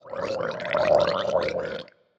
Minecraft Version Minecraft Version snapshot Latest Release | Latest Snapshot snapshot / assets / minecraft / sounds / mob / drowned / water / idle2.ogg Compare With Compare With Latest Release | Latest Snapshot